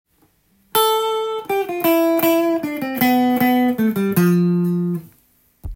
③のフレーズは、少しおしゃれな感じがしますが